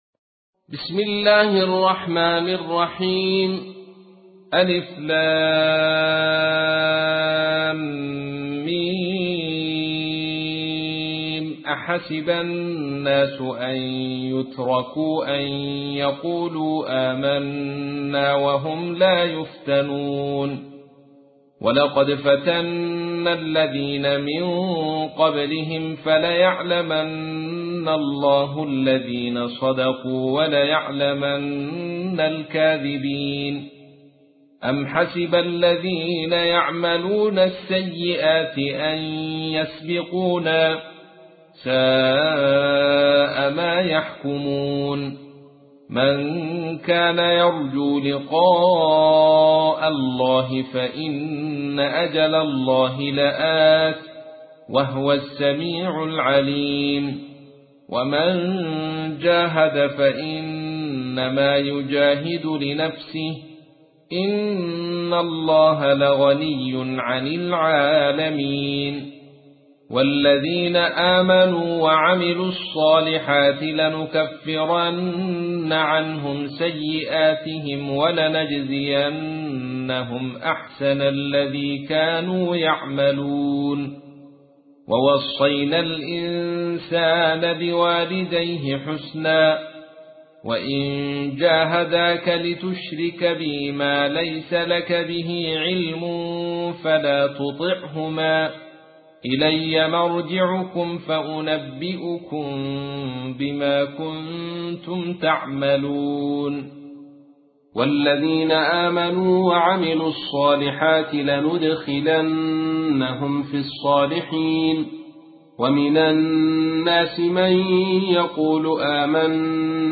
تحميل : 29. سورة العنكبوت / القارئ عبد الرشيد صوفي / القرآن الكريم / موقع يا حسين